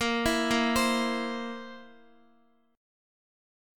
A#sus2b5 Chord